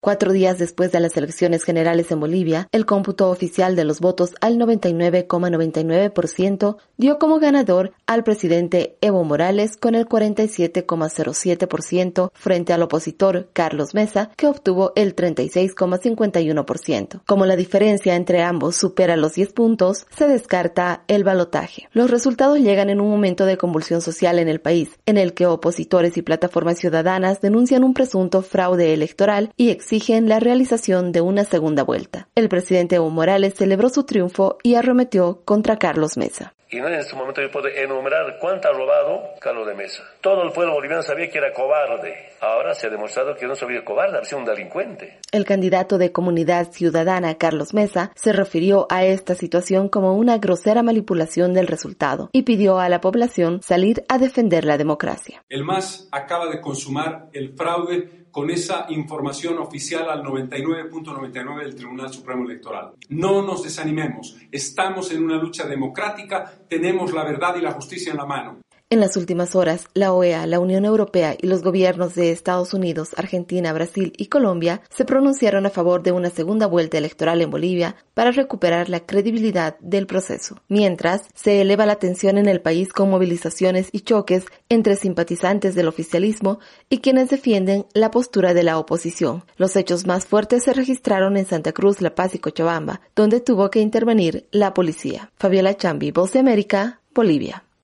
VOA: Informe de Bolivia